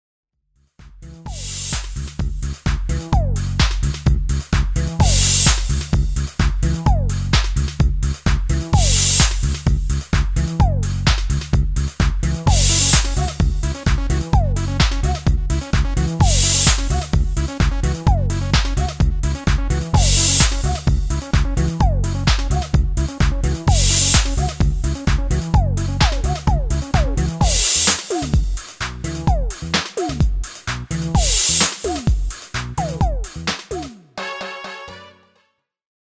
Demo/Koop midifile
Genre: Dance / Techno / HipHop / Jump
- Géén vocal harmony tracks